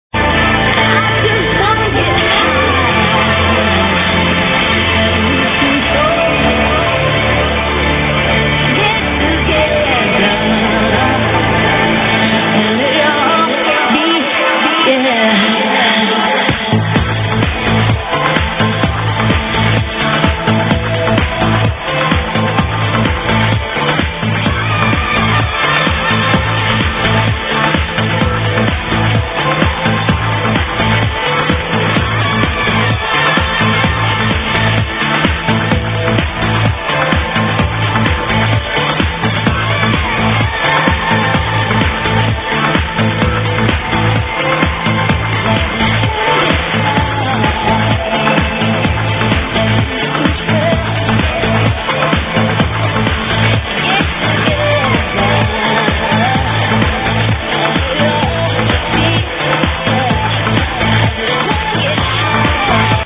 Female Vocal...
local melbourne radio